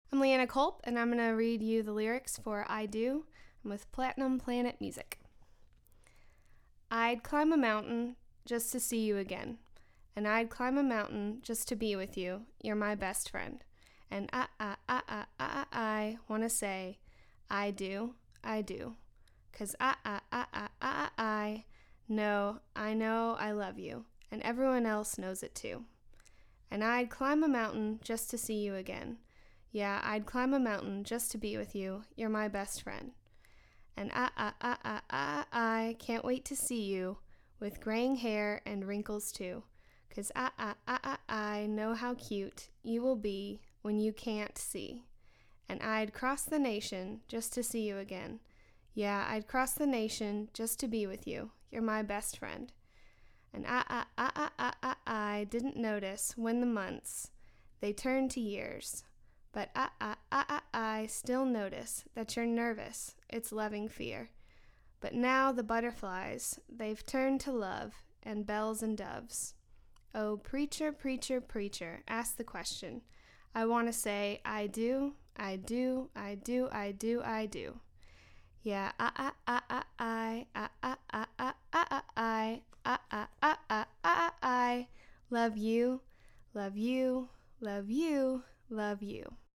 Audio Lyric Reading
I-DO-READING-1.mp3